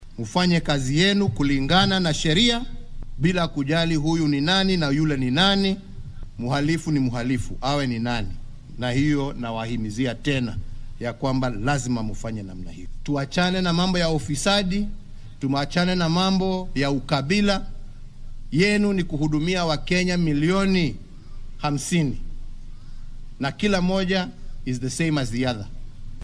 Arrimahan ayuu ka hadlay xilli uu shalay ku sugnaa machadka qaran ee booliska ee Kiganjo ee ismaamulka Nyeri.